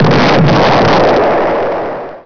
zep_explosion.wav